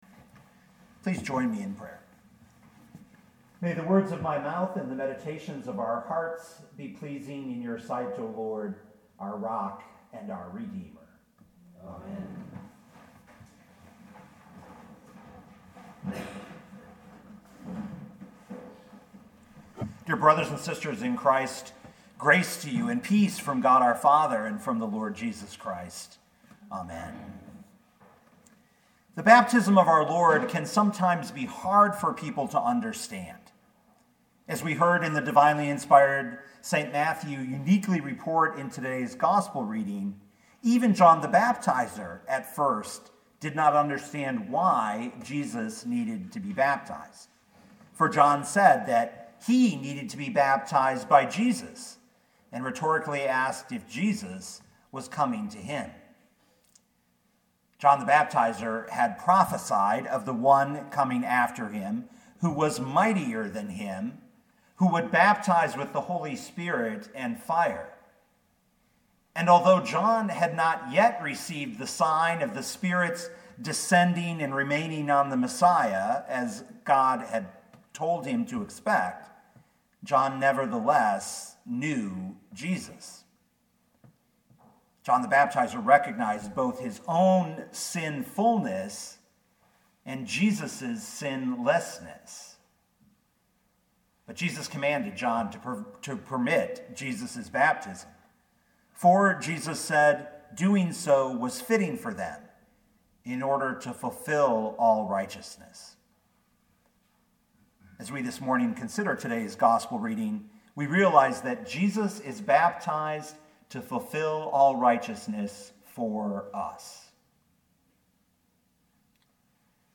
2020 Matthew 3:13-17 Listen to the sermon with the player below, or, download the audio.